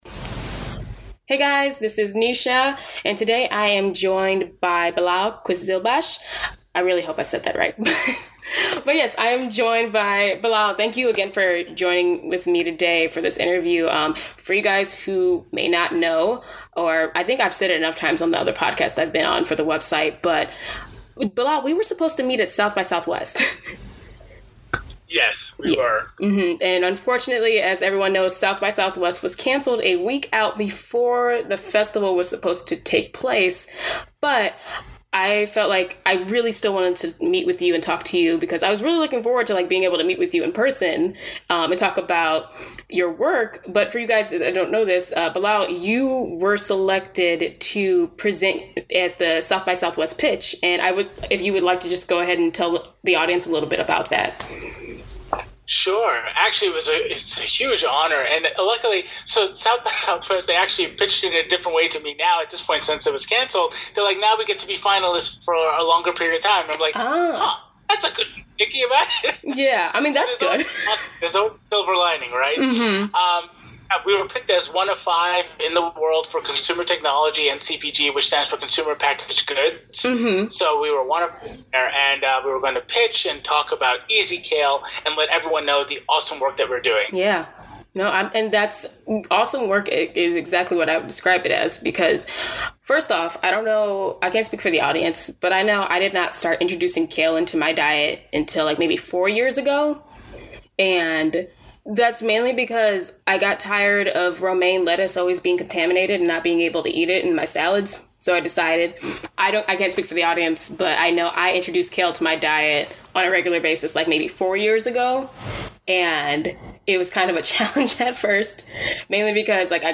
Interview - Making Super Food Accessible